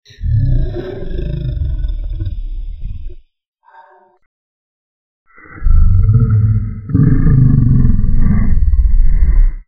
Dinosaur Dragon Growls